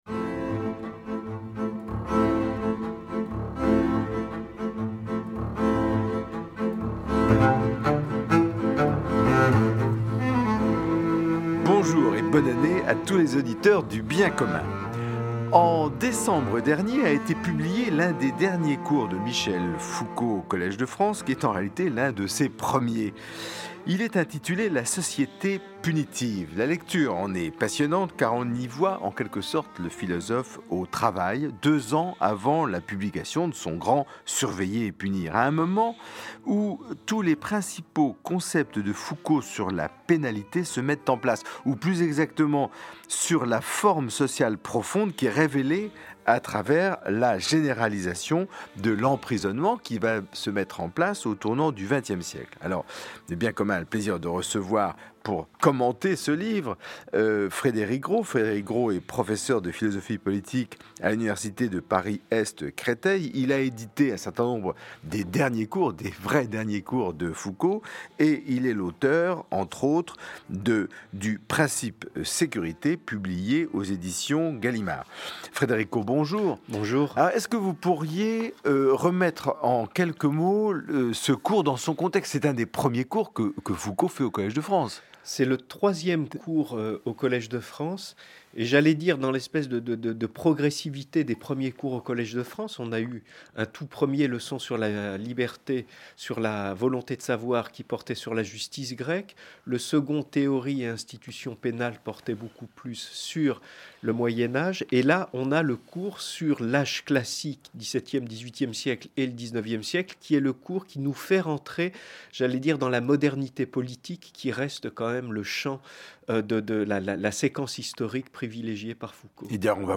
Invité(s) : Frédéric Gros, professeur de philosophie à l’université Paris XII.